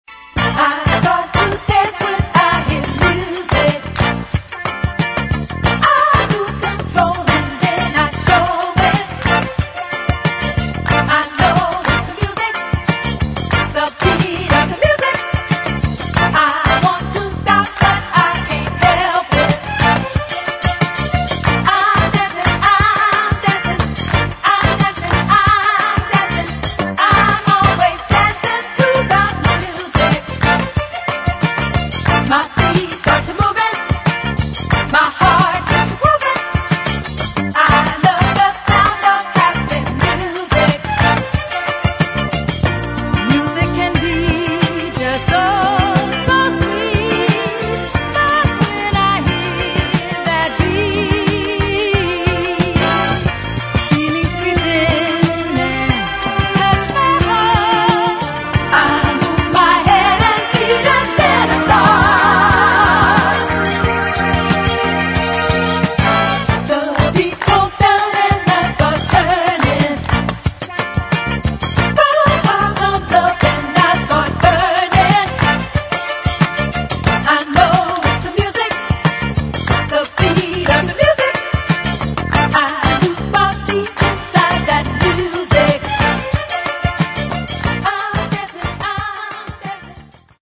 [ DISCO / FUNK ]
MONO ”play